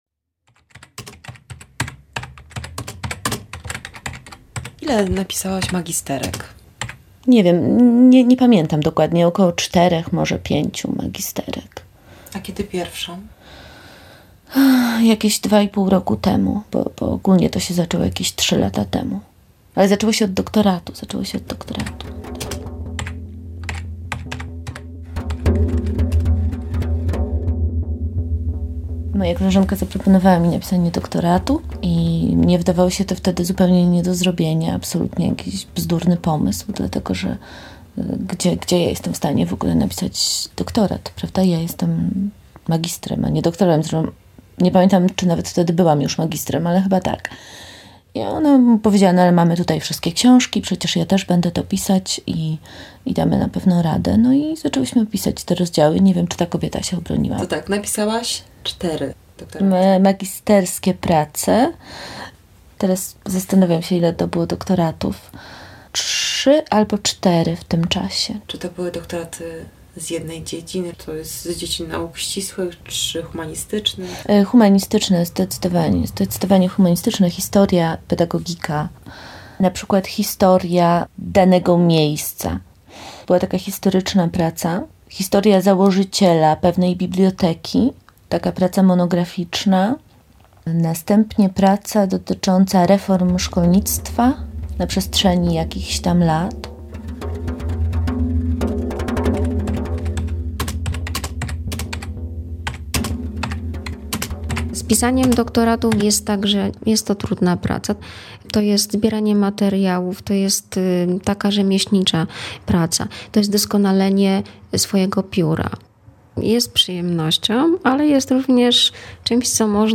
Rozmowa o poczuciu wartości. Czy tytuły i nagrody mogą dawać iluzoryczne poczucie wartości?